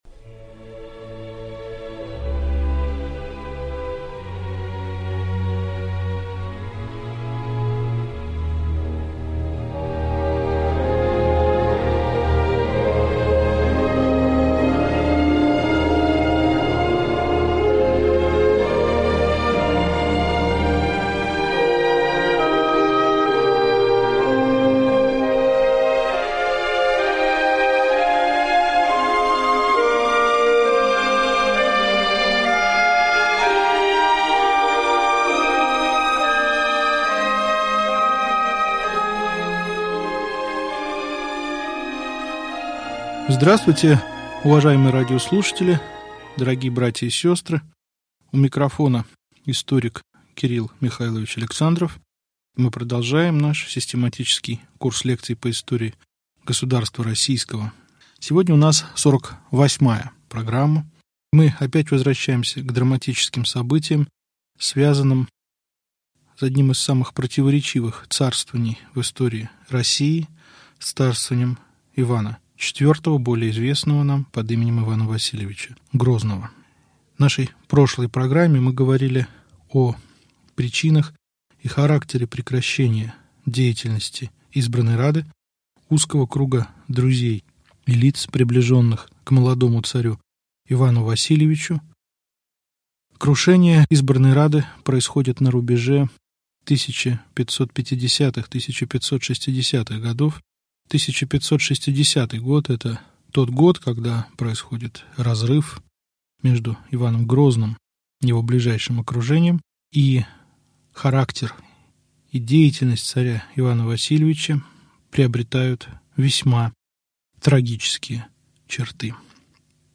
Аудиокнига Лекция 48. Правление Ивана Грозного. Начало гонений | Библиотека аудиокниг